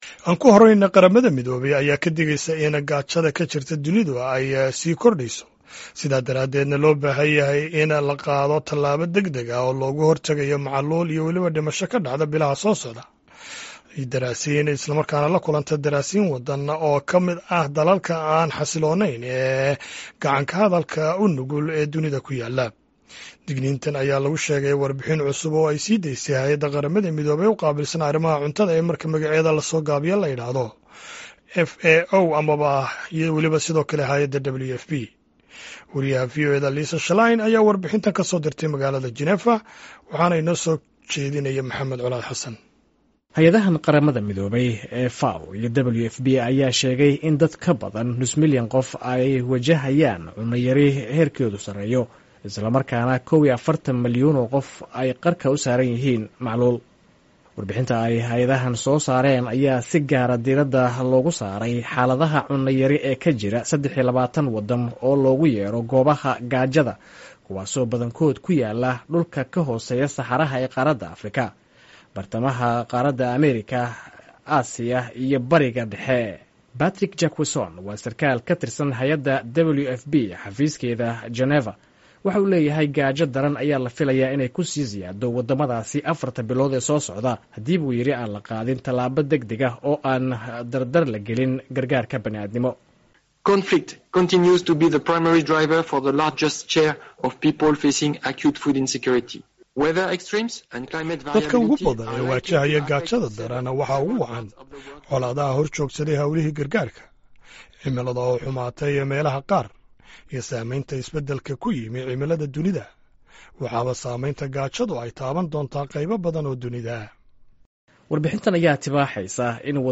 Warbixin: QM oo ka digtay macluusha sii kordheysa ee caalamka